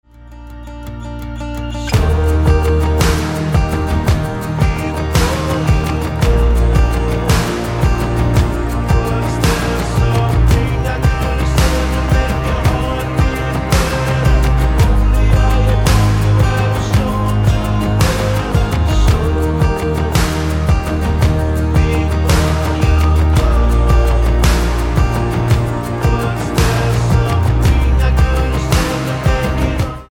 Tonart:Eb mit Chor